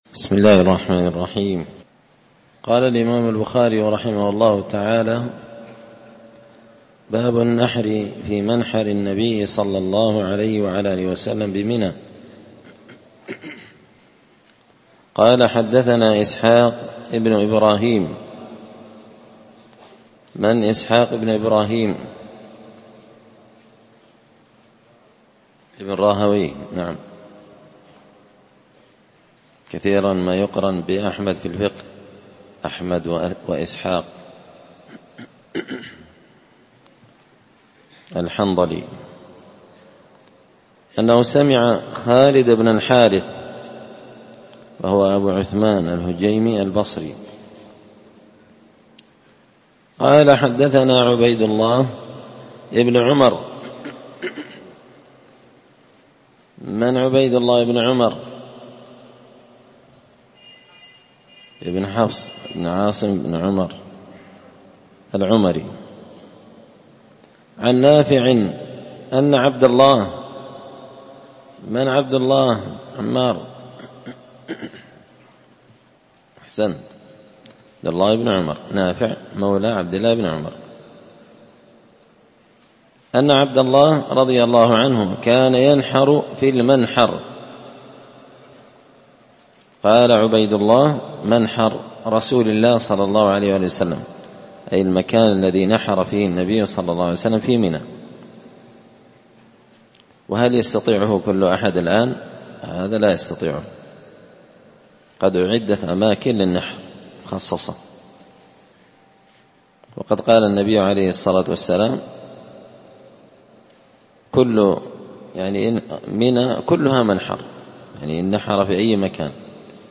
كتاب الحج من شرح صحيح البخاري – الدرس 104